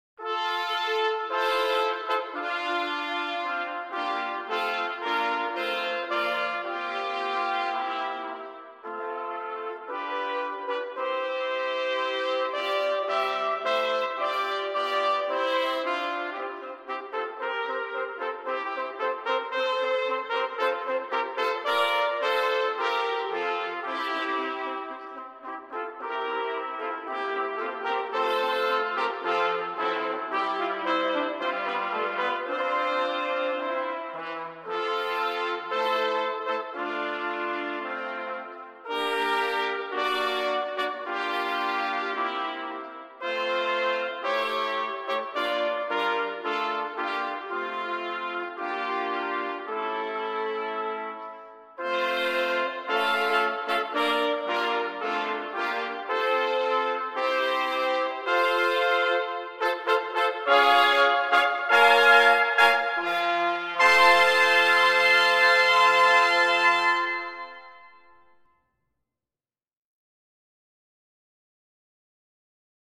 Number of Trumpets: 4
Key: F concert